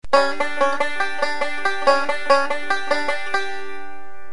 This is especially evident in the “Foggy Mt. Breakdown Roll” as some call it.
Check out these Reno variations of the “Foggy Mt. Breakdown” lick.